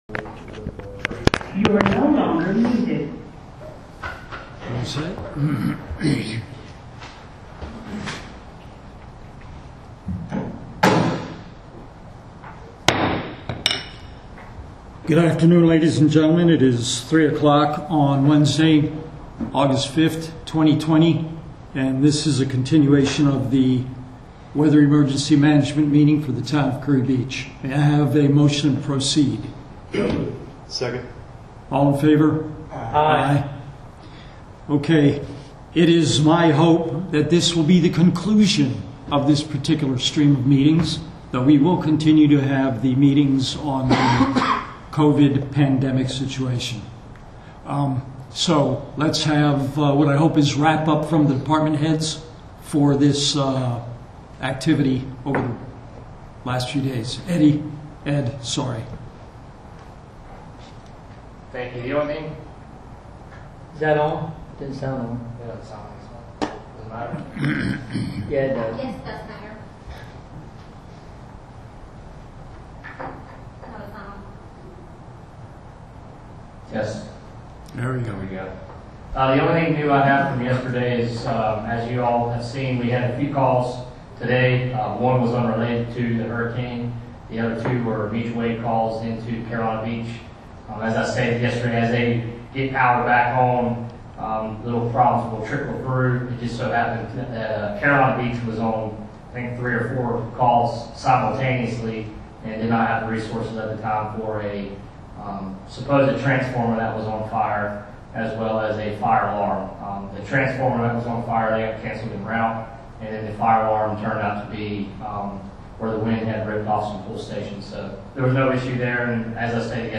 Town Council Meeting - Emergency Hurricane Isaias